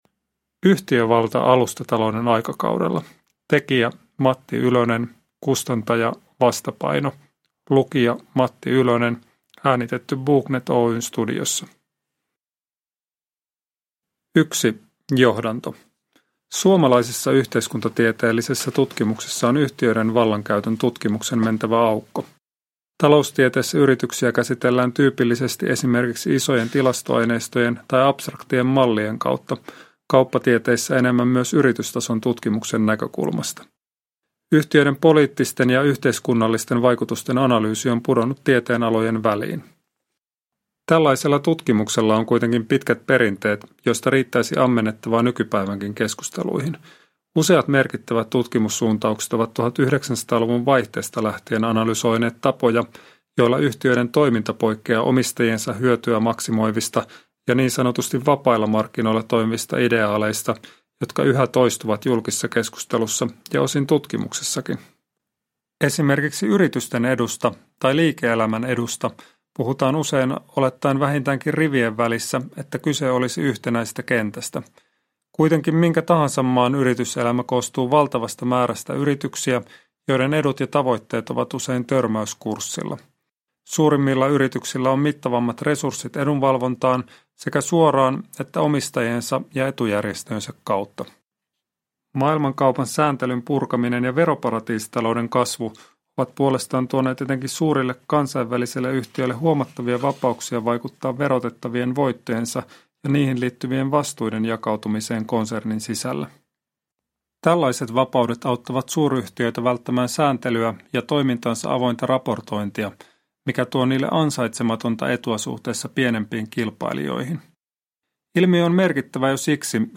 Yhtiövalta alustatalouden aikakaudella (ljudbok) av Matti Ylönen